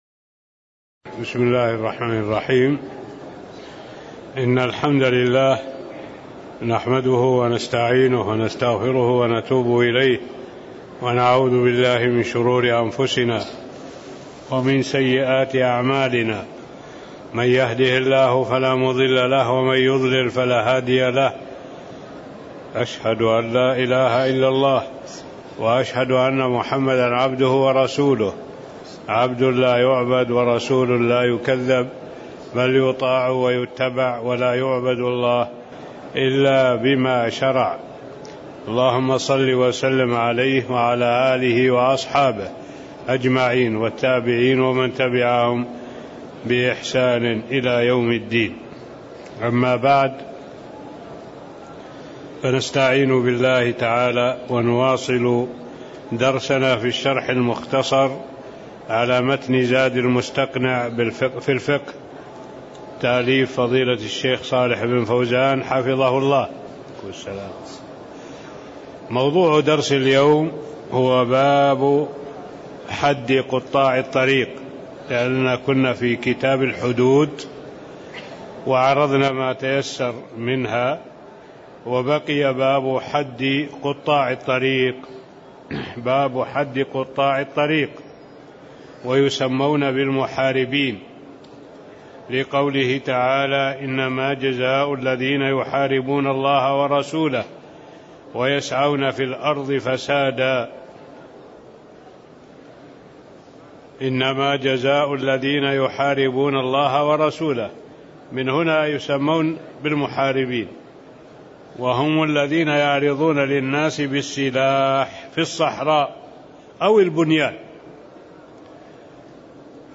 تاريخ النشر ٤ ذو القعدة ١٤٣٥ هـ المكان: المسجد النبوي الشيخ: معالي الشيخ الدكتور صالح بن عبد الله العبود معالي الشيخ الدكتور صالح بن عبد الله العبود باب حدّ قطّاع الطريق (08)